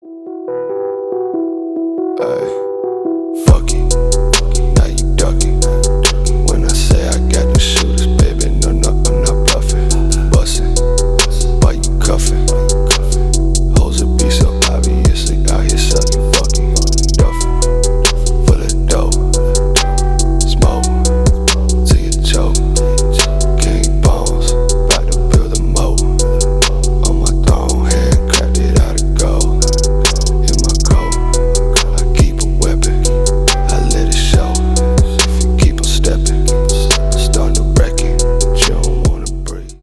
Хип-хоп
Дуэт Красивый женский голос